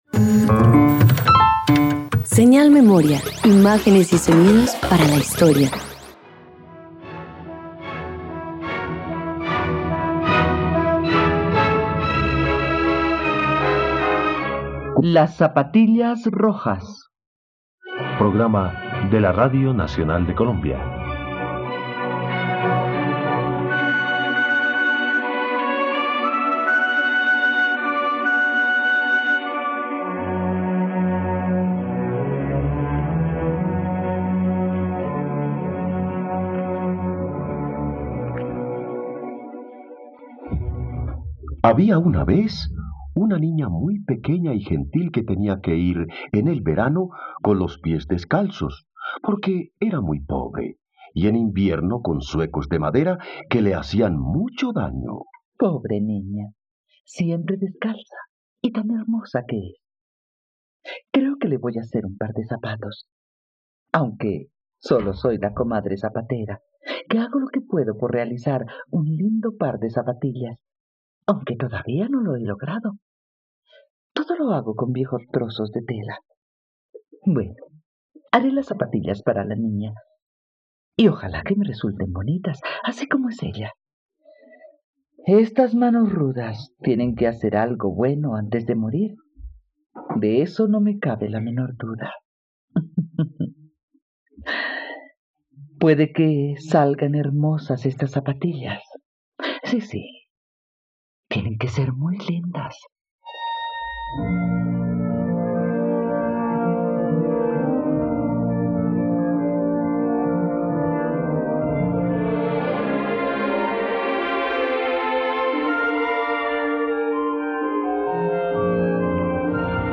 No te pierdas el clásico de "Las zapatillas rojas" en versión para radioteatro aquí, en RTVCPlay.
Hans Christian Andersen, autor de “Las zapatillas rojas”, destaca las situaciones aparentemente inocentes que viven los niños y que resultan en complejos acontecimientos. Asset ID 0 Arriba 0% Down 0% Producción Radioteatros dominicales Tags radioteatro Niñez niños paradoja Duración 22Minutos Archivo 30_LAS_ZAPATILLAS_ROJAS_EDITADO.mp3 (49.48 MB) Número de capítulo 30